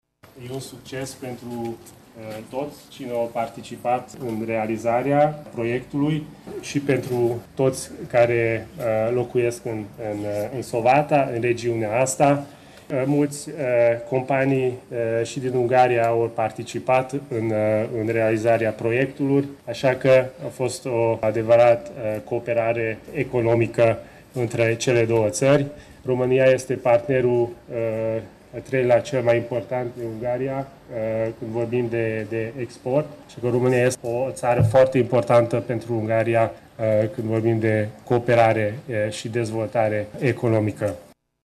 Prezent și el la festivități, secretarul de stat adjunct din Ministerul Ungar de Externe, Kiss-Parciu Péter, a salutat realizarea noii investiții și a subliniat că România este unul dintre cei mai importanți parteneri economici ai Ungariei: